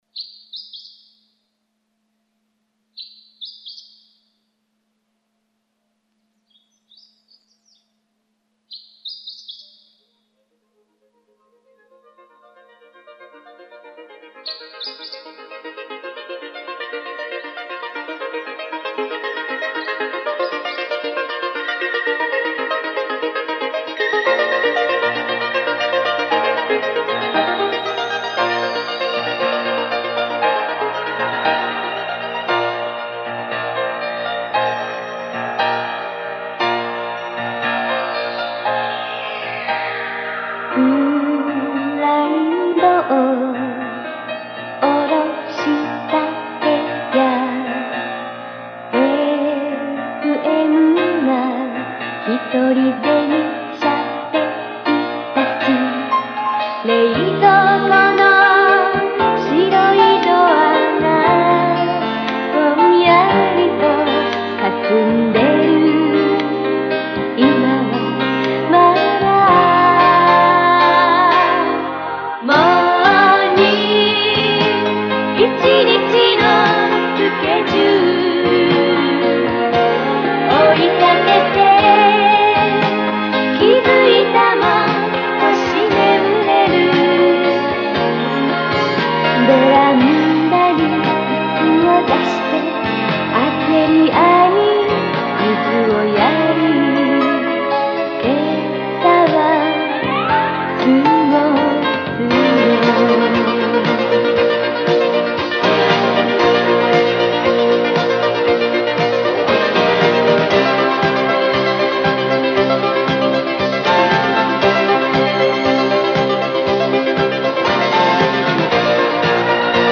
いつの日か何かするために購入したSONYのM-830というボイスレコーダーで再生してみます．
確かに普通のマイクロカセットテープよりは音質が良いようにも思えますが，そもそもこのM-830がモノラル仕様な上に再生可能周波数300～4000Hzなのでテープの実力を発揮しきれてないように思えます．